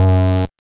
1708R BASS.wav